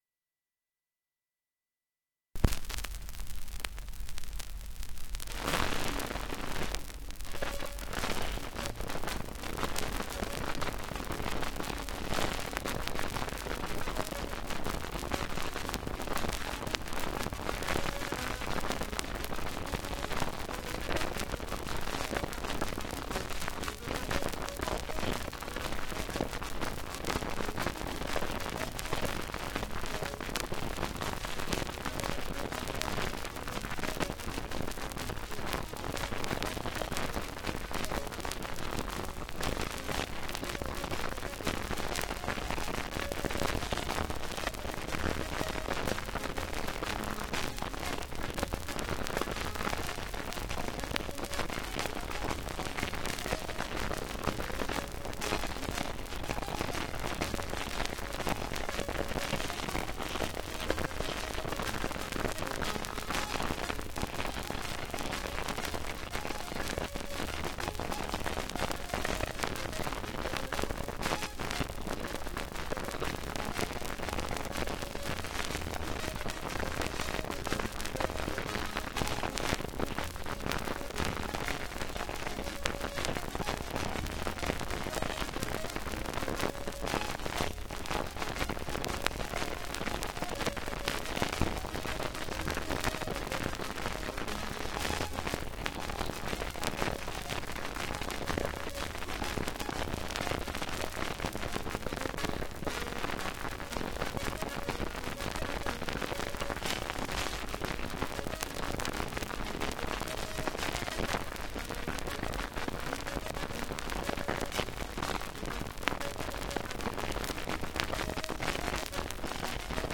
1 10 inch 78rpm shellac disc